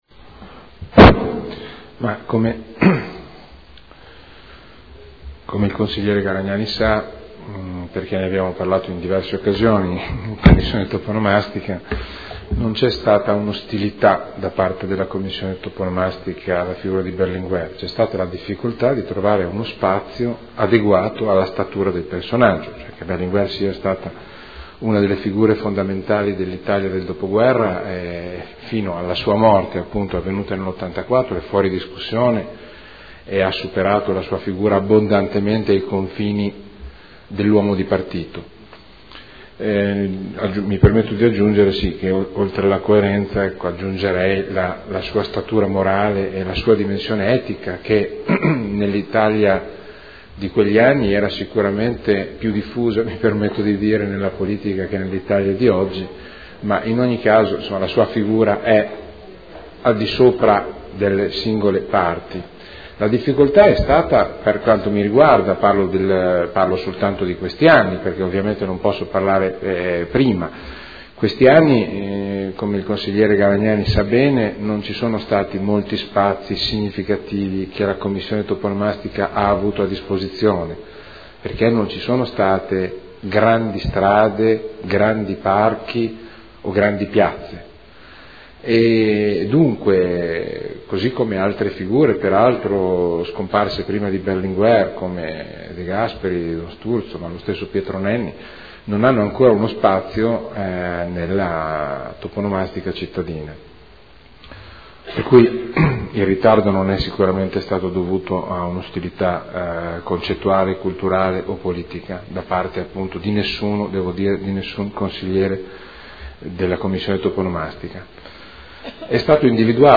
Interrogazione dei consiglieri Garagnani, Andreana, Gorrieri, Trande (P.D.) avente per oggetto: “E’ possibile onorare la memoria di Enrico Berlinguer, a quasi trent’anni dalla sua morte, prima della scadenza dell’attuale consigliatura, attribuendogli un luogo della città degno del suo spessore morale e politico?”. Risponde l'assessore